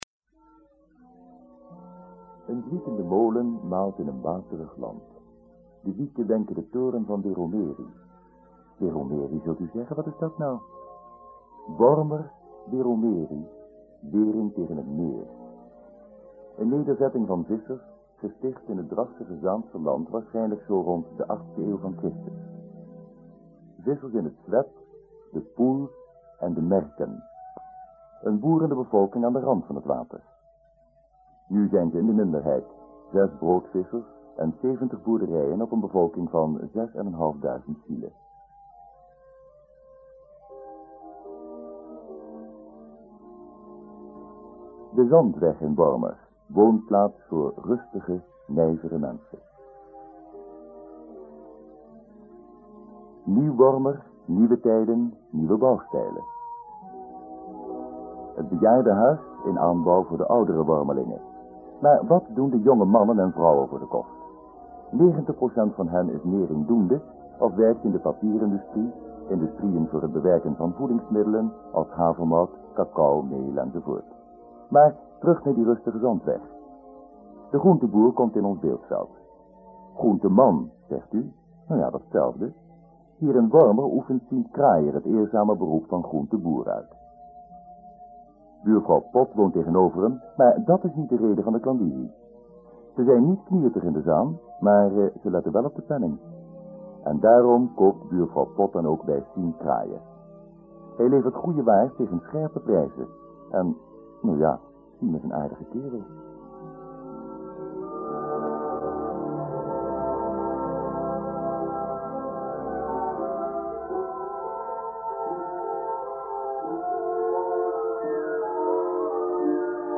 Het interview wordt vooraf gegaan door een gesproken impressie van Wormer van 5:02 minuten.
Na de impressie begint het feitelijke interview.
De geluidsband bleek opgenomen met 4 sporen mono op een snelheid van 9,5 cm per seconde.